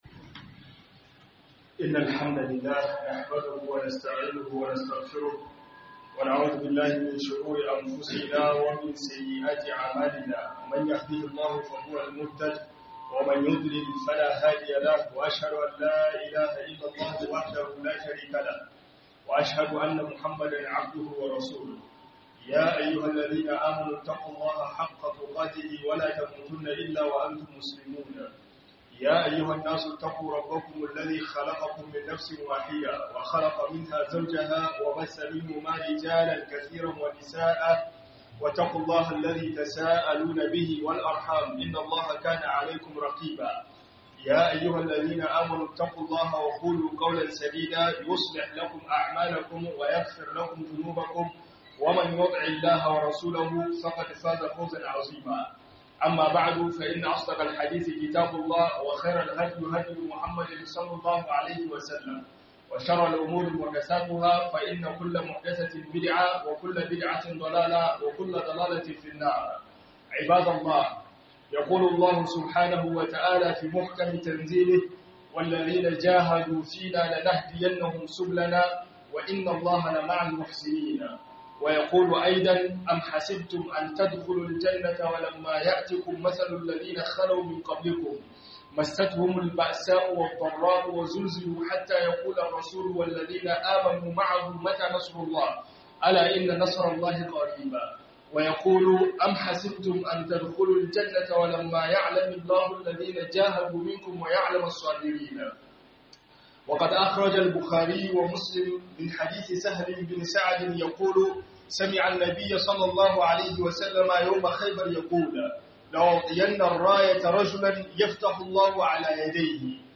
HIDIMAR ADDINI - HUDUBA